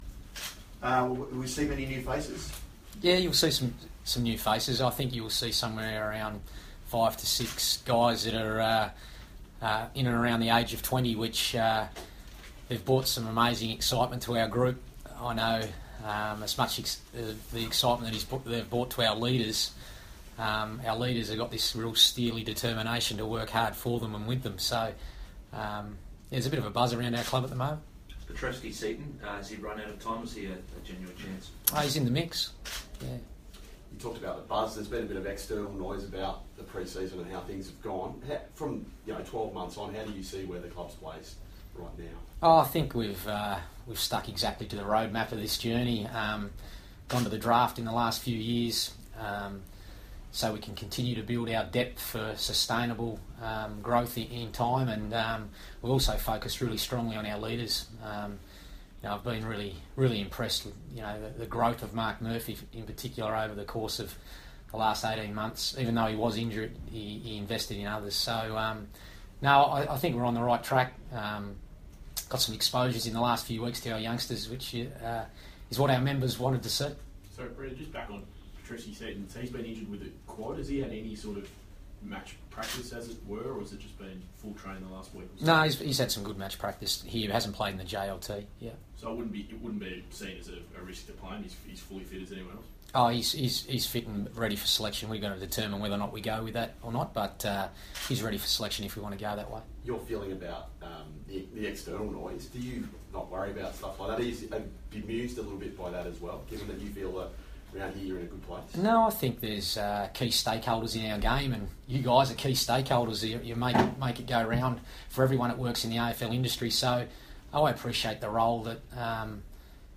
Brendon Bolton press conference - March 22
Carlton coach Brendon Bolton speaks to the media ahead of the Blues' season opener against Richmond on Thursday night.